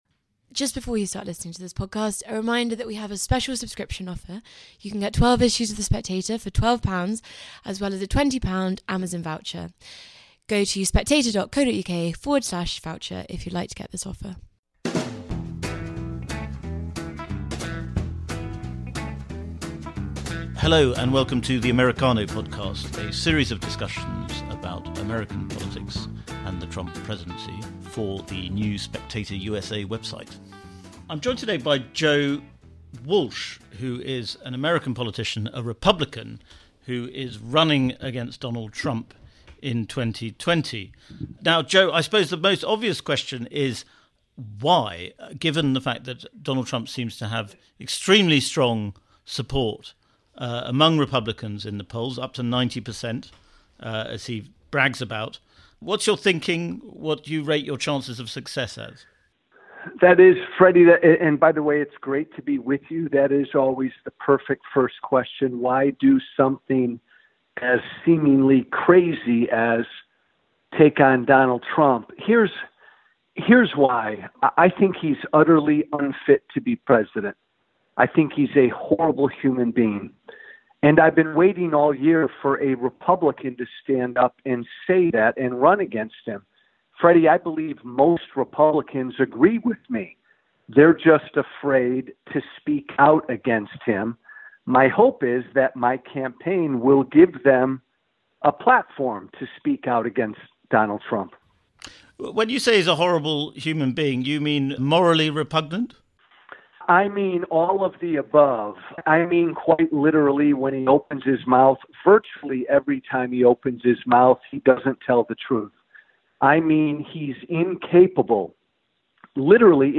With Joe Walsh, a conservative talk radio host who is challenging Trump for the Republican presidential nomination. Americano is a series of in-depth discussions on American politics with the best pundits stateside.